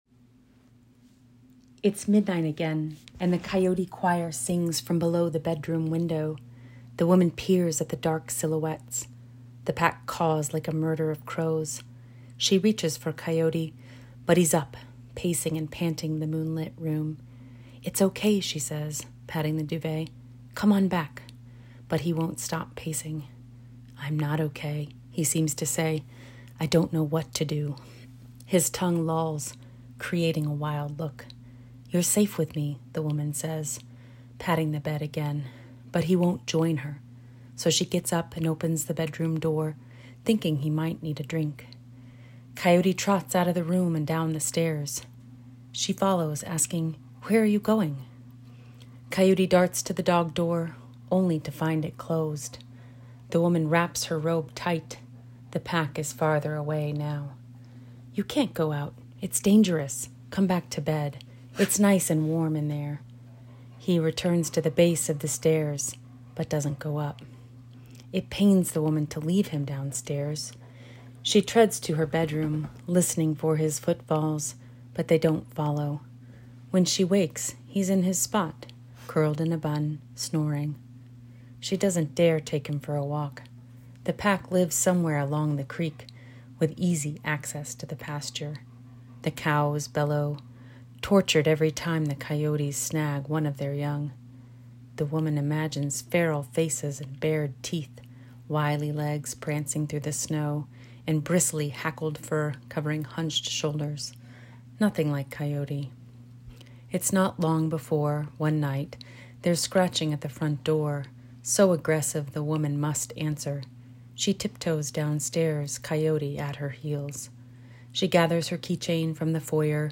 reading of "Coyote"